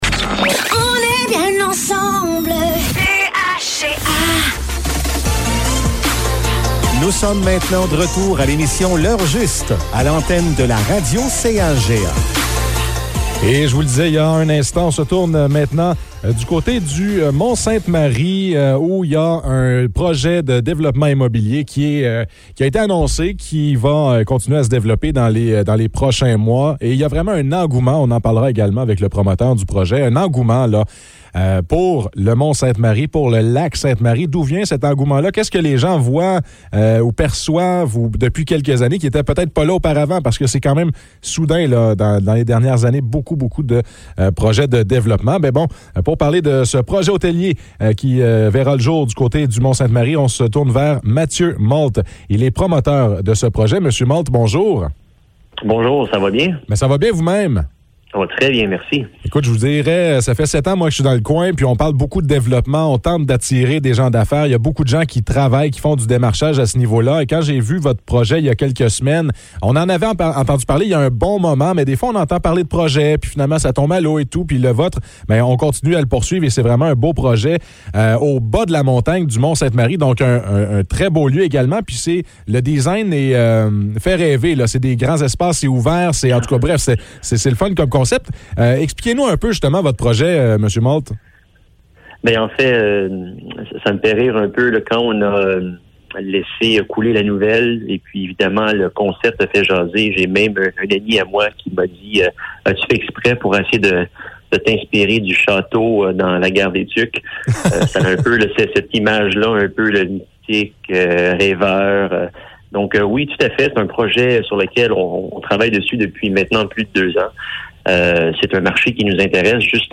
Entrevues